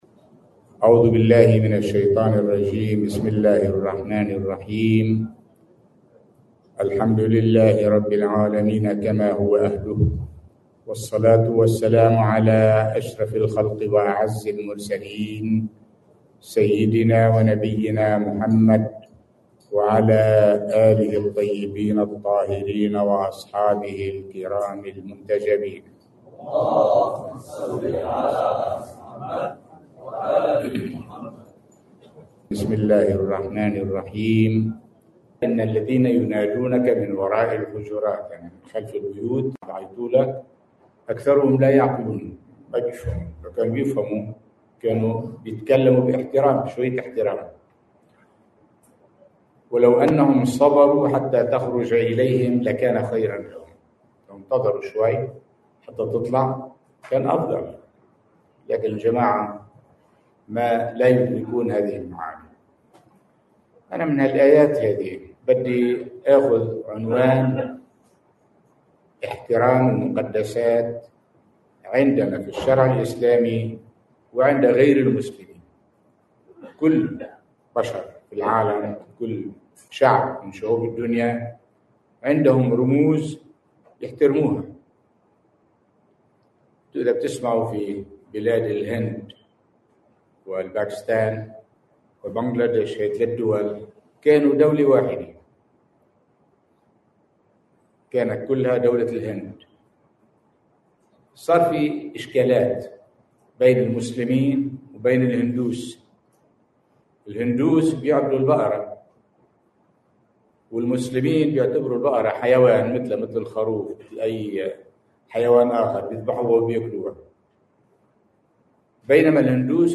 في دكار في قاعة المؤسسة الإسلامية الاجتماعية وعبر البث المباشر في الليلة الثالثة من محرم الحرام
◾ المحاضرة الثانية من محرم 1445هـ.